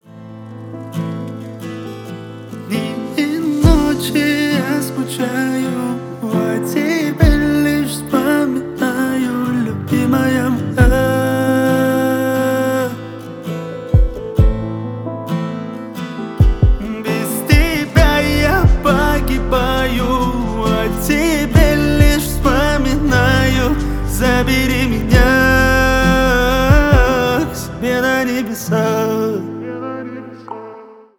Поп Музыка
кавказские # грустные